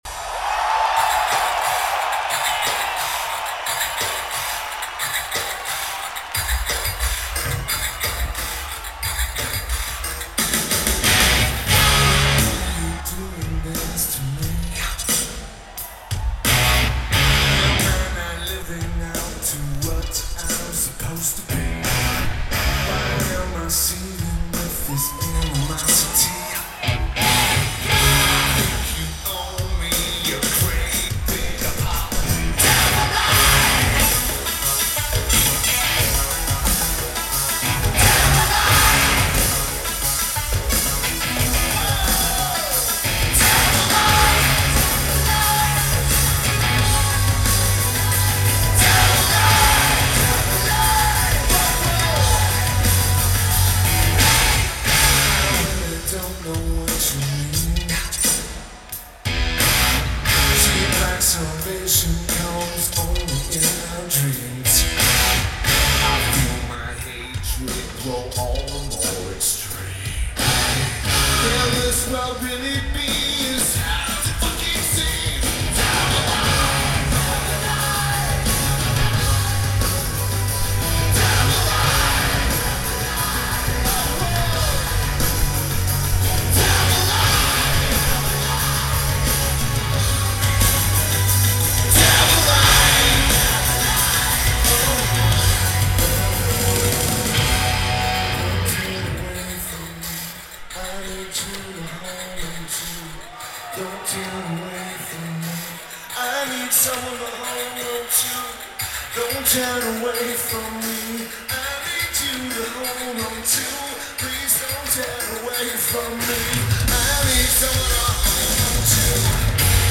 Monterrey Arena
Lineage: Audio - AUD (SP-CMC-2 + SP-SPSB-9 + Sony MZ-M200)